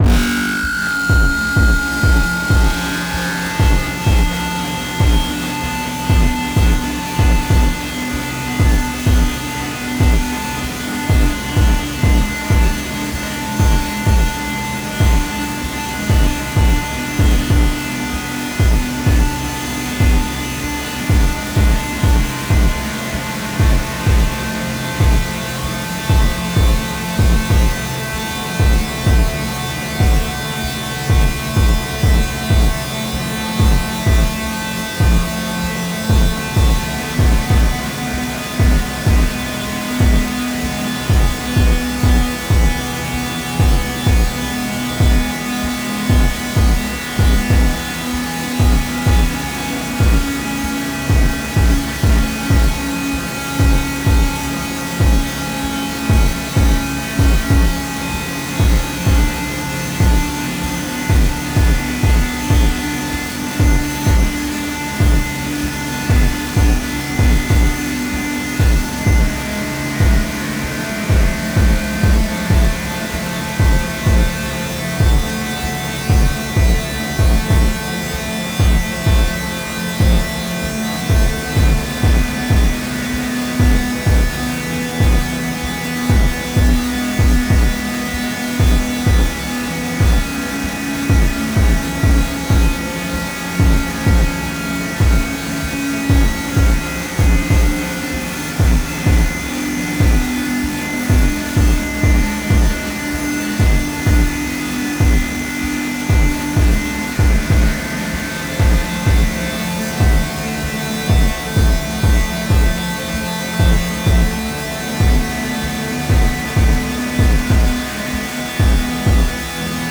大地を轟かす太く重い狼の”鼓動”。
天と地を結ぶ霊獣の為の、美しく力強い祝祭曲。
たいへんデリケートで複雑なパンニングと、スペクトル分布を特徴とするアルバムです。
現代音楽、先端的テクノ、実験音楽をお好きな方々にはもちろん、ギター愛好家の方々にもお薦めのアルバムです。